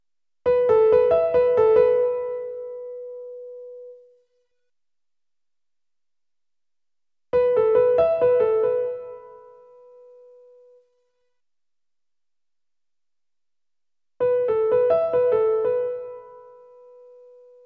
まず左のメロディーは全くのベタうち。（要するに表現なしということですね）
真ん中はベロシティー だけを表現したもの。
右側はベロシティー 、ディレーション（長さ）両方表現したもの。
piano.mp3